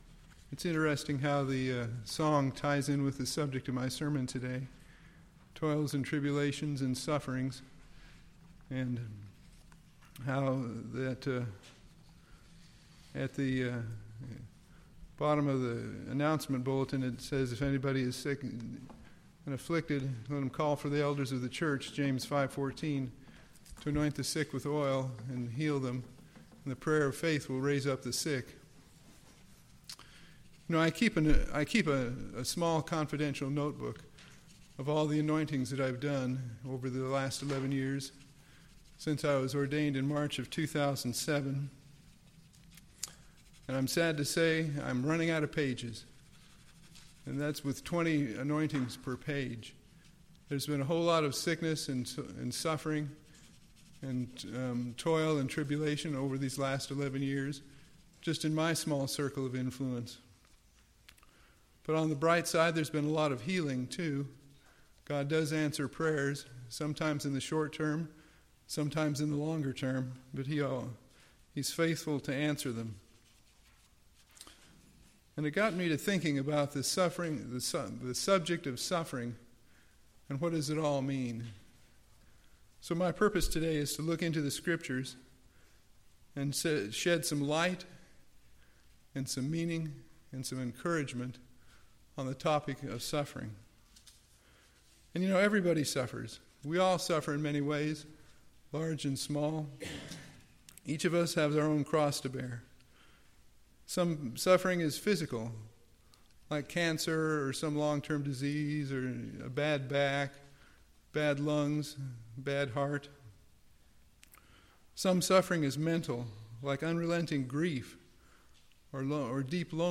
We all suffer in many ways throughout life and all of varying lengths of time. This sermon looks into the scriptures to shed some light, meaning, and encouragement on the topic of suffering.